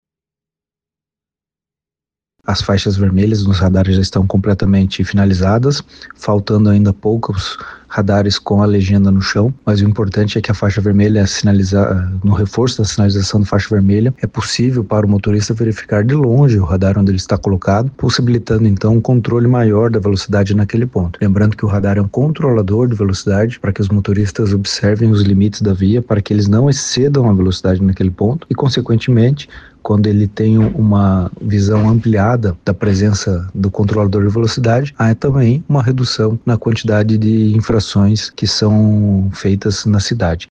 O superintendente de Trânsito de Curitiba, Bruno Pessutti, explicou que implantação da nova sinalização dos radares já estava na fase final.